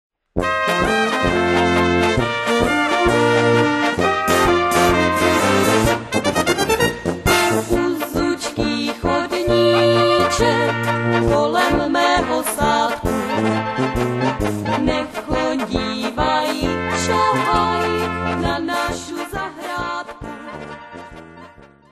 polka
valčík